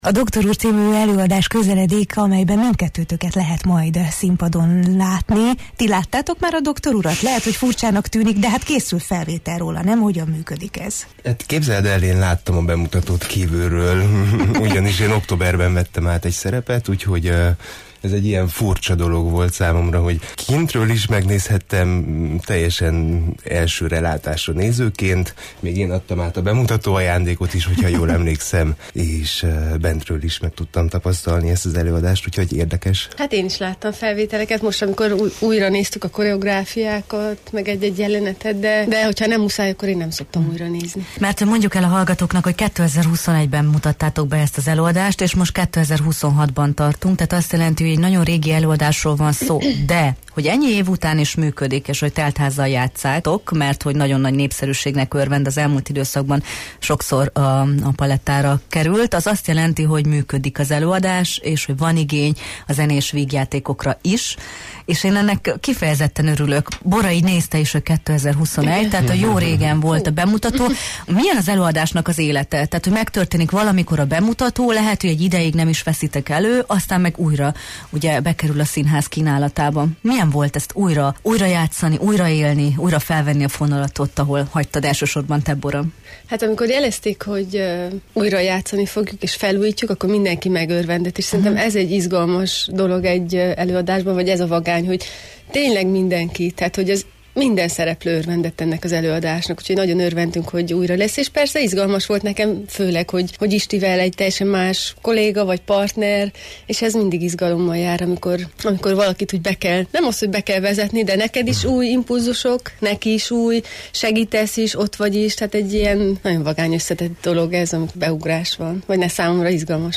színművészekkel beszélgettünk az előadás kapcsán a Jó reggelt, Erdély!-ben